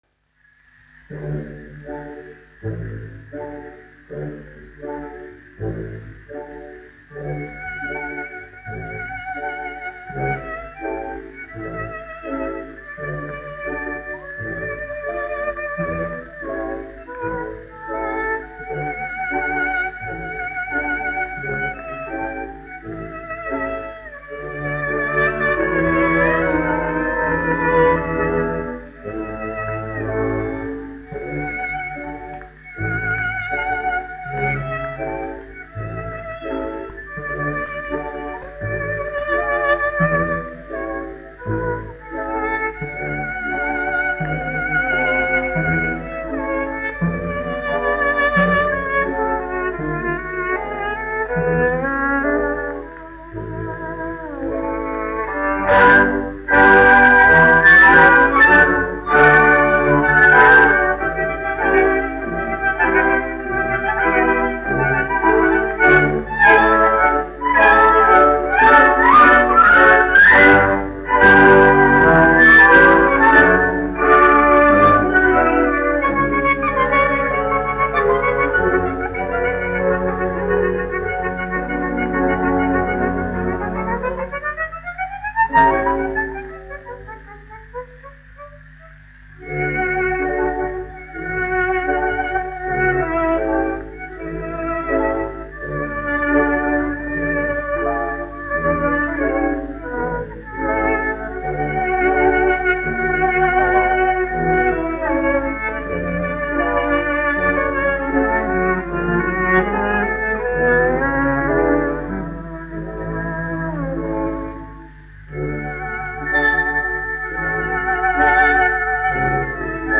1 skpl. : analogs, 78 apgr/min, mono ; 25 cm
Vijole ar orķestri, aranžējumi
Latvijas vēsturiskie šellaka skaņuplašu ieraksti (Kolekcija)